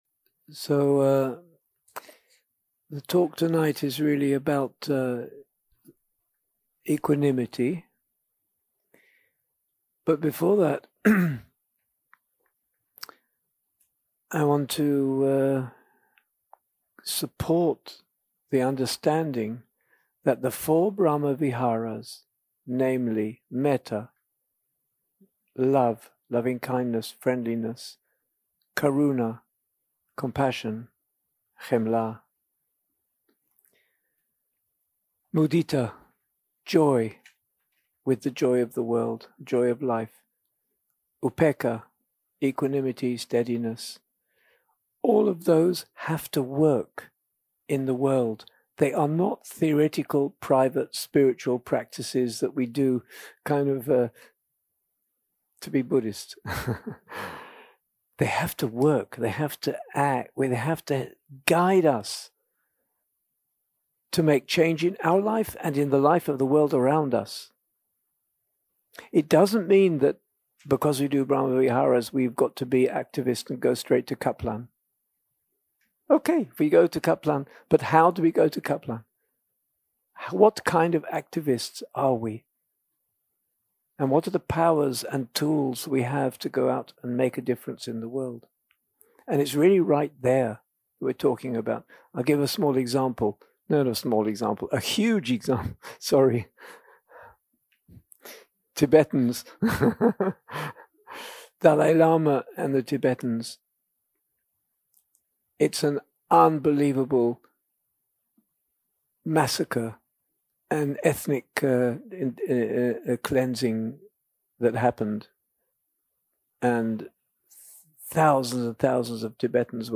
יום 6 – הקלטה 16 – ערב – שיחת דהארמה - Staying Steady When All Is Collapsing
יום 6 – הקלטה 16 – ערב – שיחת דהארמה - Staying Steady When All Is Collapsing 0:00 0:00 סוג ההקלטה: Dharma type: Dharma Talks שפת ההקלטה: Dharma talk language: English